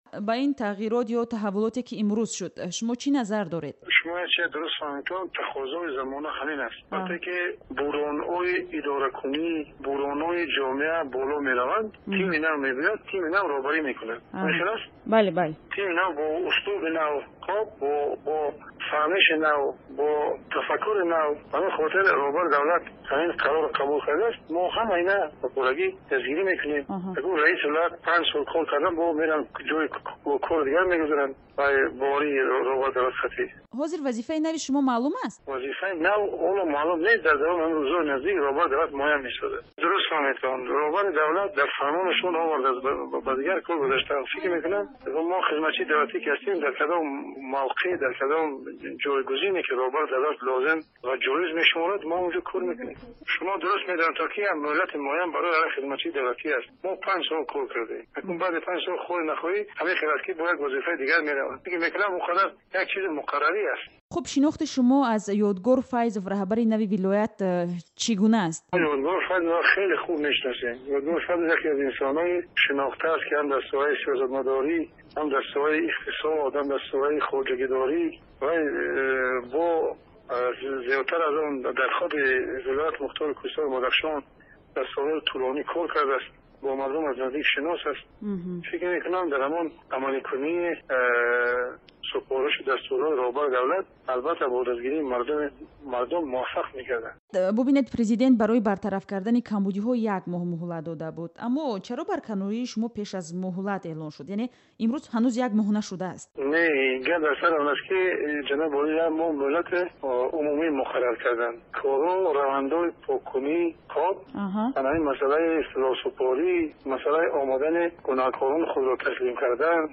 Шодихон Ҷамшед баъди чанд соати барканорӣ аз мақоми раиси Вилояти Мухтори Кӯҳистони Бадахшон дар суҳбати ихтисосӣ бо Радиои Озодӣ аз шаҳри Хоруғ гуфт, ки корашро дар вазифаи дигар идома хоҳад дод.
Гуфтугӯ бо Шодихон Ҷамшед, баъди чанд соати барканорӣ